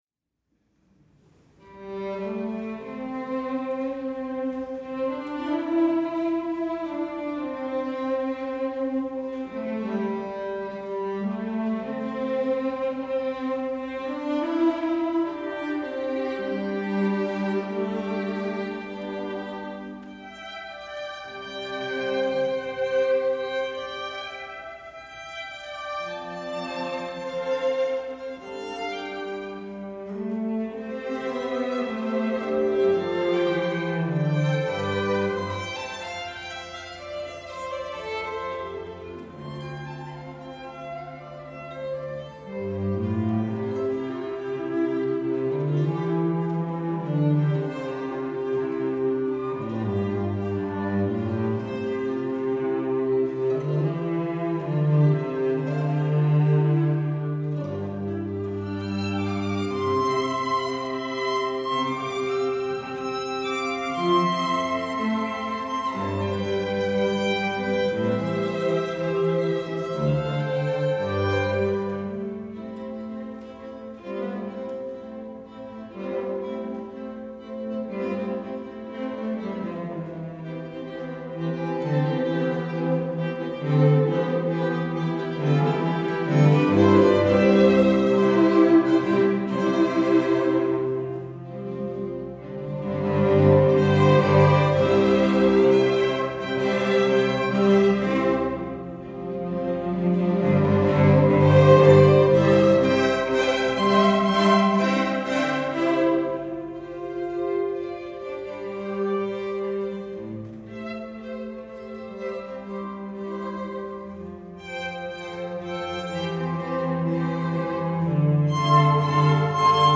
for String Quartet (2014)